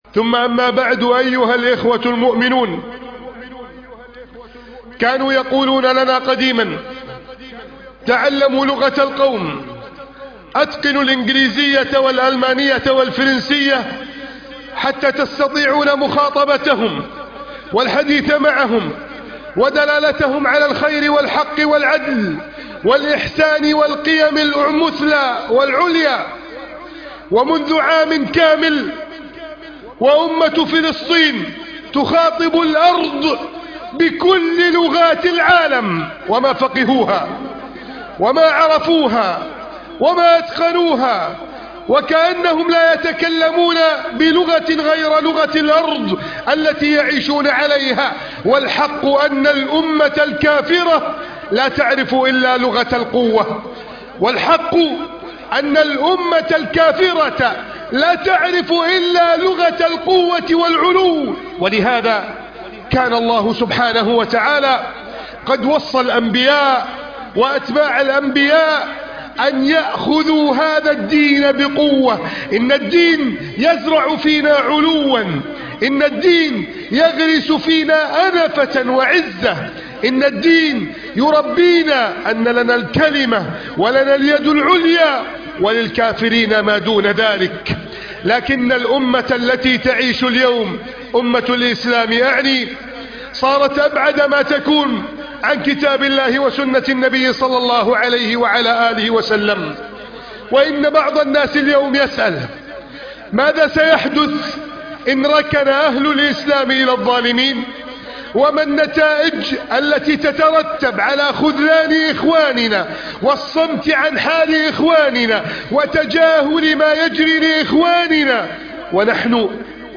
الخطبة الممنوعة من العرض